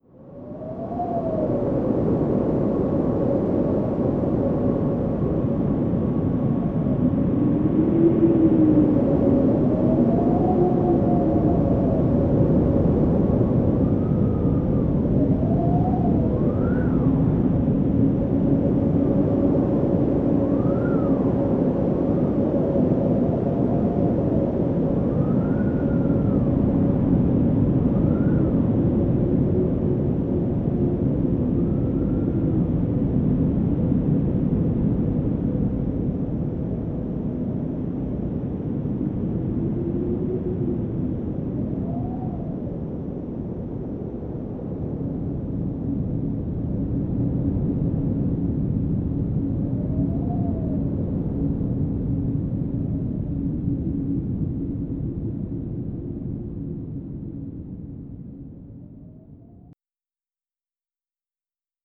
windAmbiance.wav